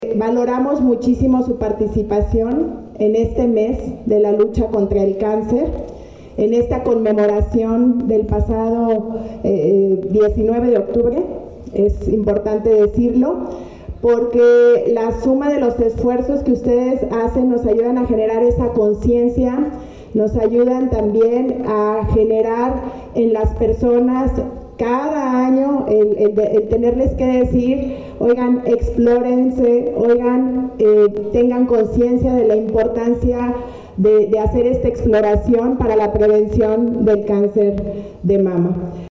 AudioBoletines
Irapuato, Gto. 24 de octubre del 2024 .- Más de 600 vacantes de empleo se ofertaron durante la Feria de Enlace Laboral Incluyente realizada en el patio de la Presidencia Municipal.